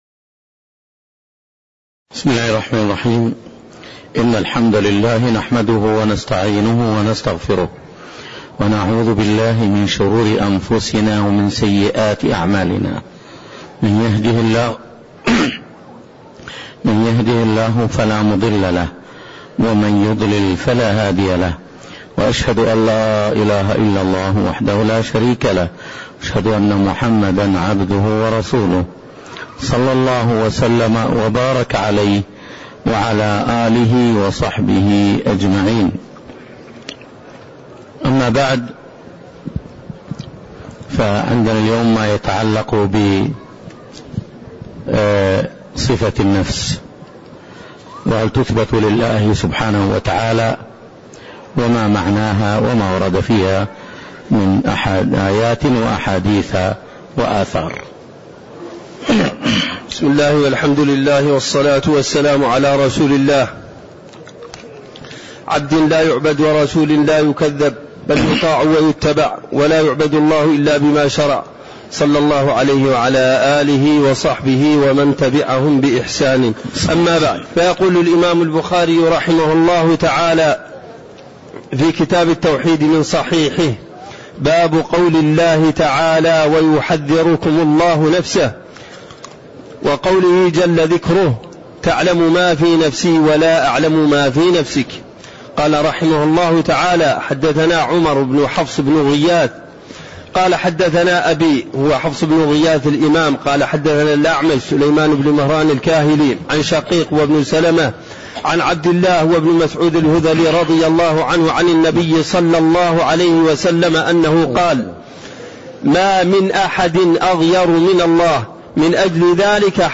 تاريخ النشر ١٨ ربيع الثاني ١٤٣٣ هـ المكان: المسجد النبوي الشيخ